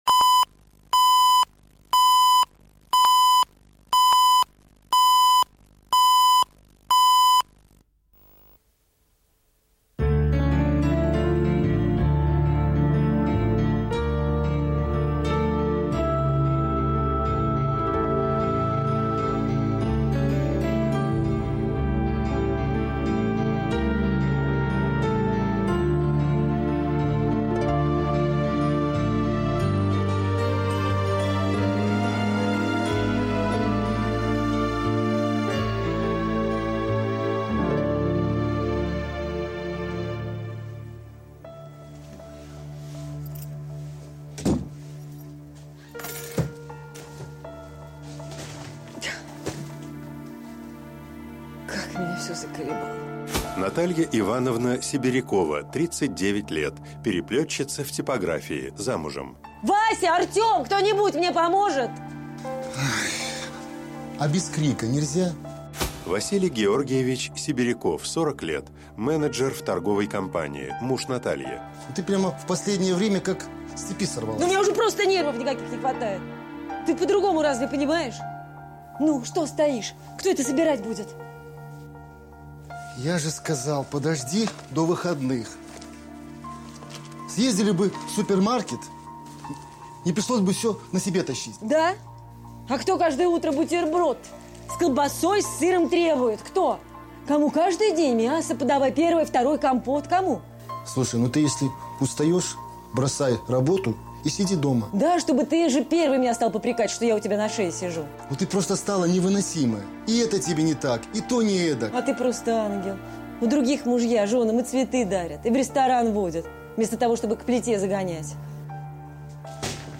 Аудиокнига Лабиринт